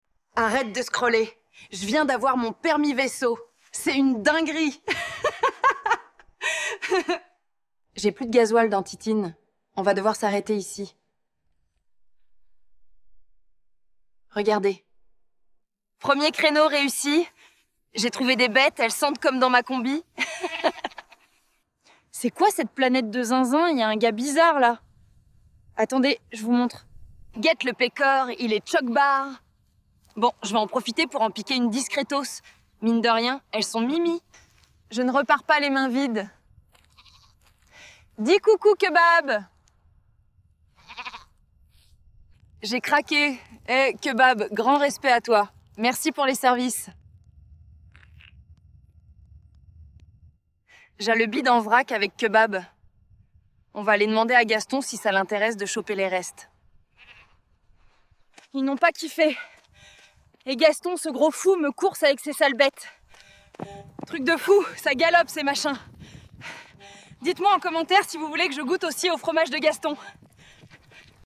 Hlasová část